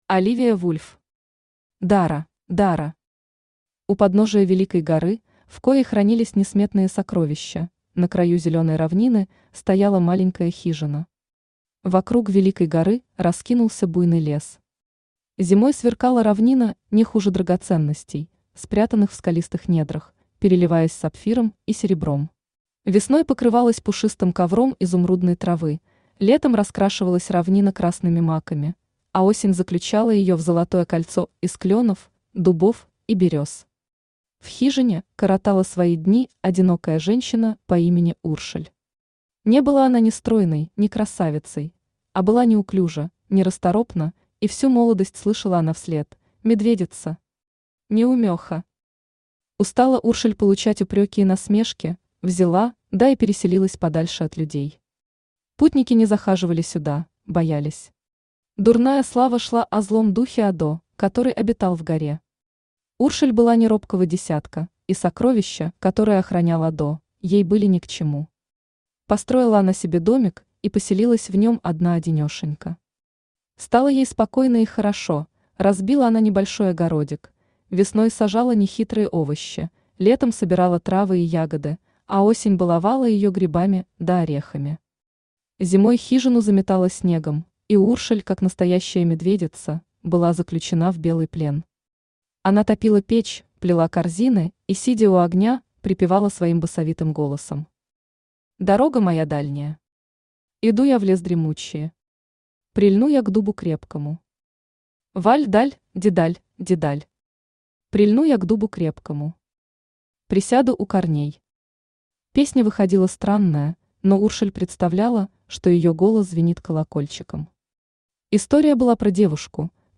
Aудиокнига Дара Автор Оливия Вульф Читает аудиокнигу Авточтец ЛитРес.